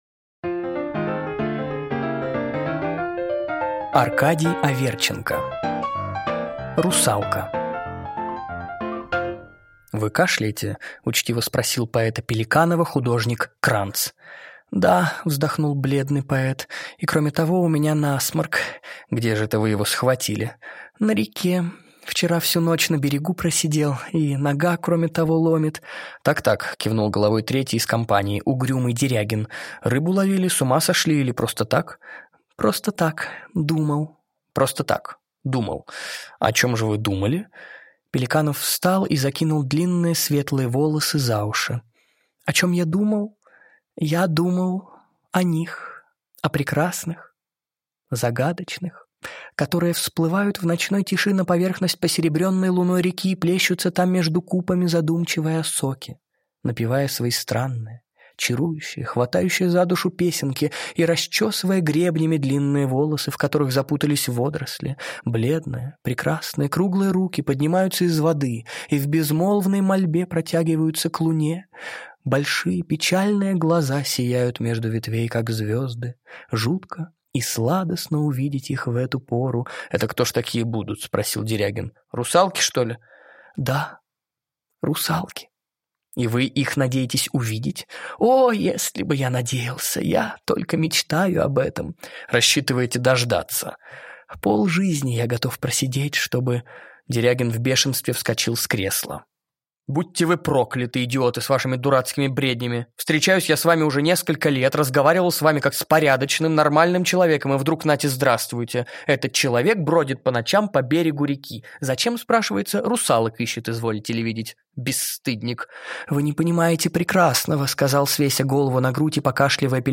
Аудиокнига Русалка | Библиотека аудиокниг